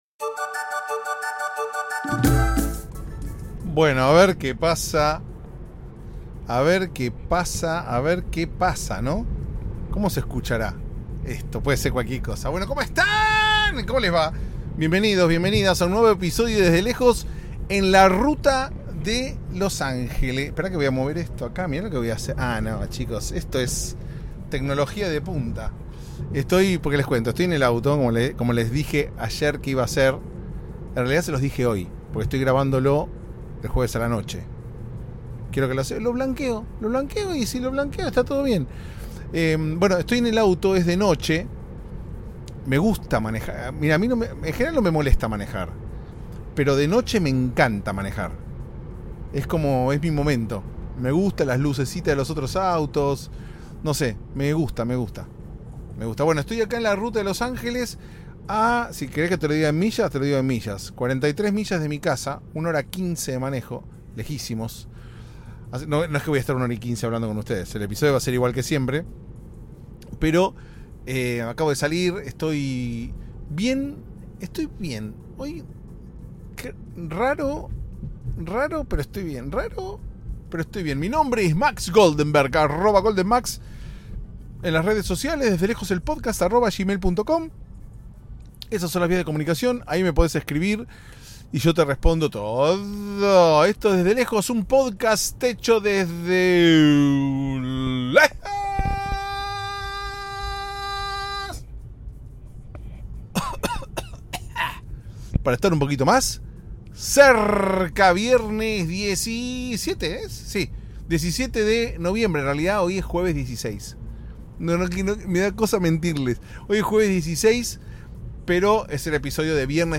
(aunque este episodio se grabó un jueves a la noche) y tu cuerpo lo sabe (el tuyo porque el mío no sabe nada de nada) Ahí vamos pues... en este episodio que fue grabado íntegramente mientras manejaba (hablame de infracciones!)